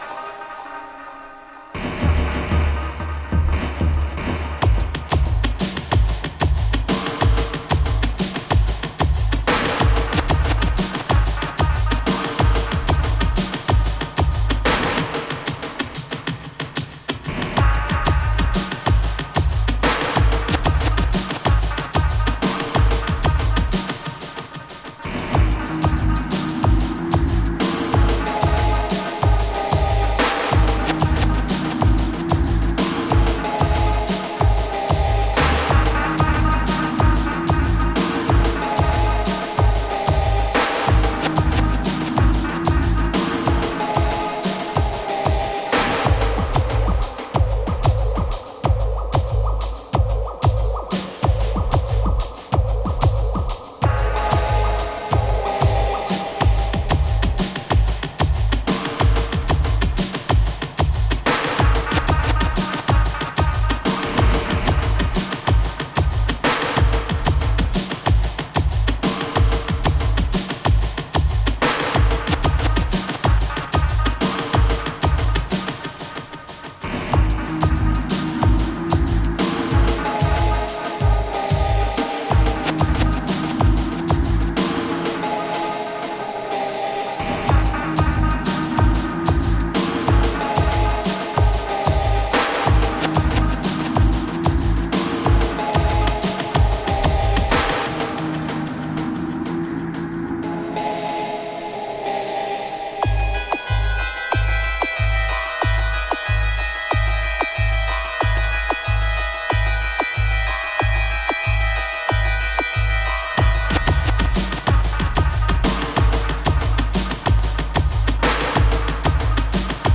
ダブヴァージョンの飛び加減、ヘヴィネスも万全。